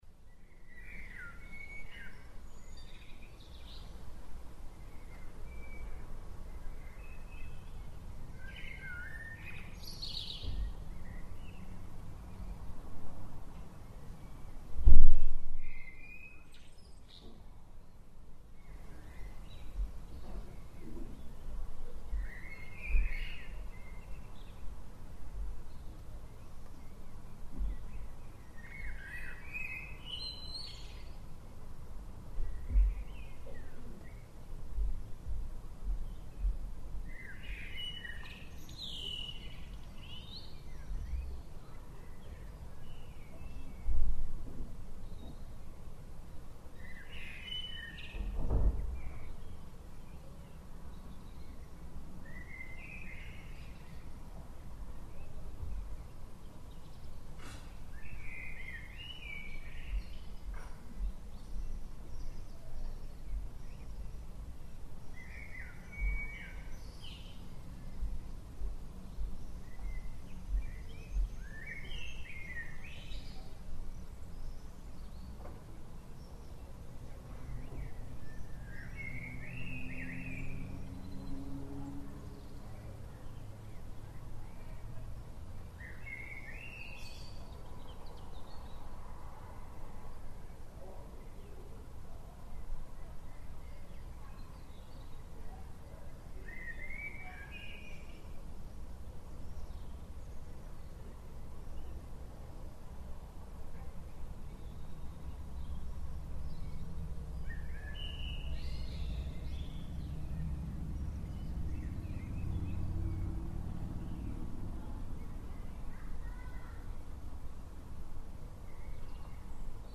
Evening Birdsong from Bedroom Window 19 June 2013